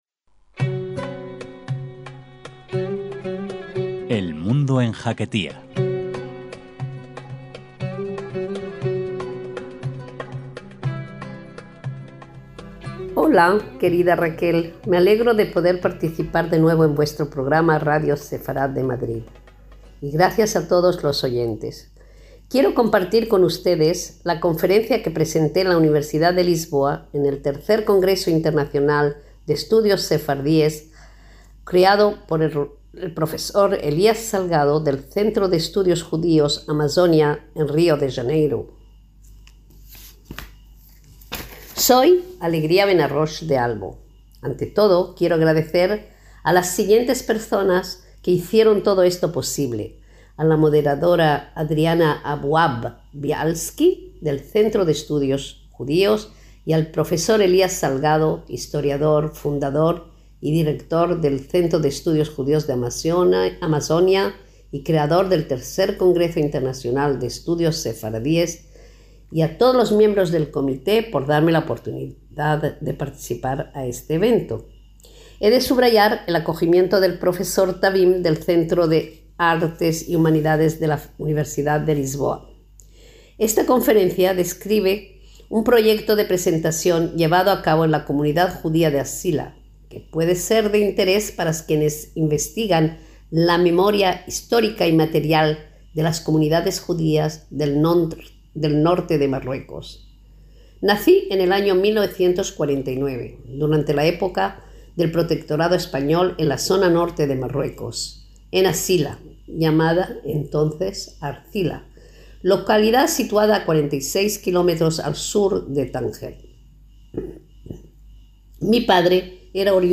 Una conferencia que es memoria viva de la comunidad judía de Arcila y del resto de comunidades judías del norte de Marruecos.